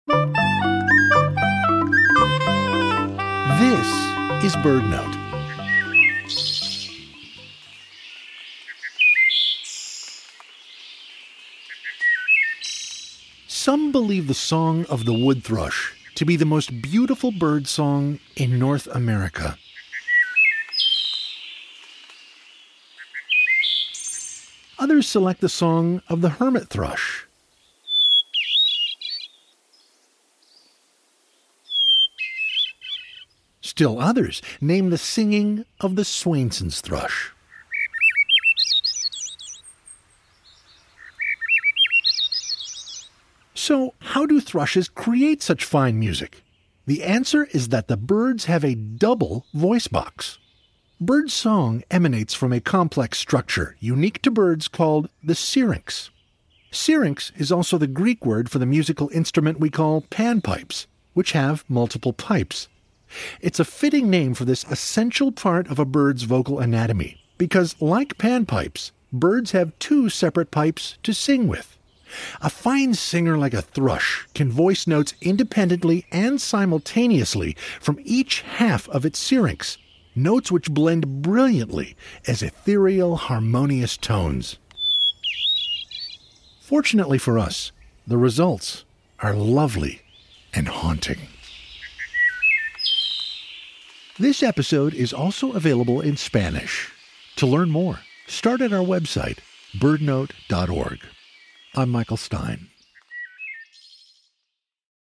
How do thrushes like this Veery create such fine music? The answer is that the birds have a double voice box, unique to them, called the syrinx. A fine singer like a thrush can voice notes independently and simultaneously from each half of its syrinx, notes which blend brilliantly as ethereal, harmonious tones.